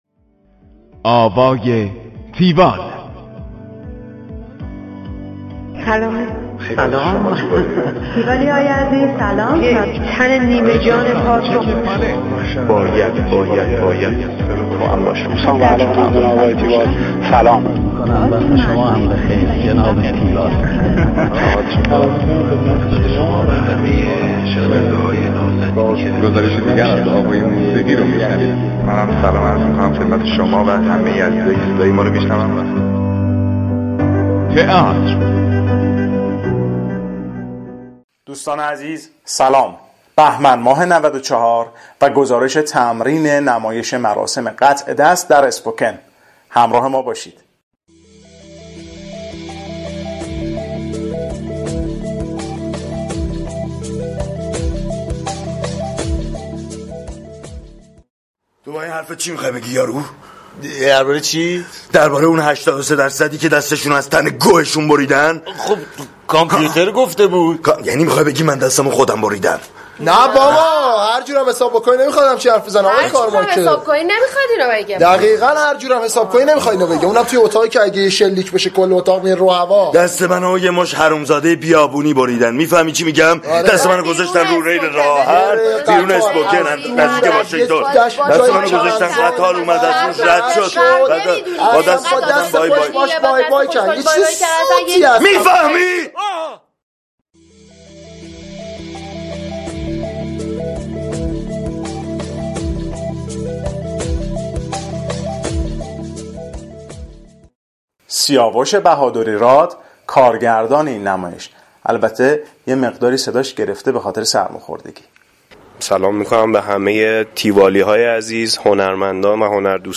گزارش آوای تیوال از نمایش مراسم قطع دست در اسپوکن